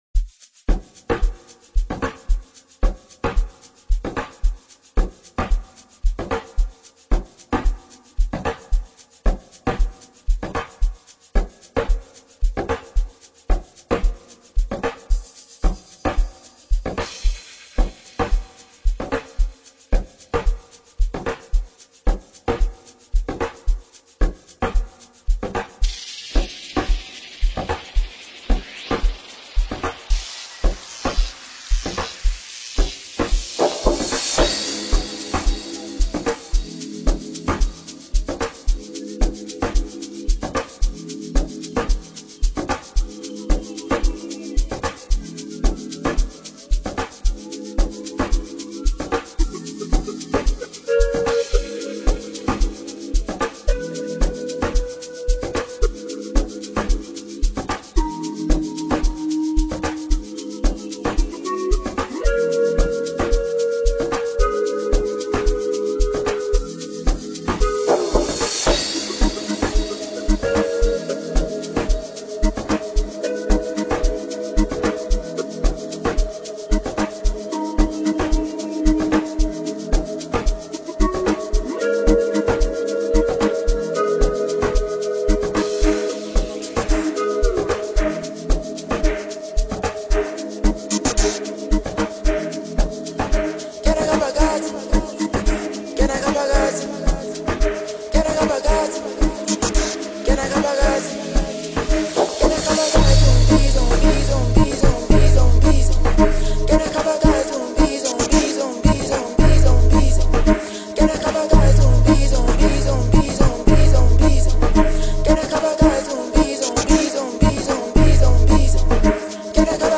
rising piano group
banging scorcher
Amapiano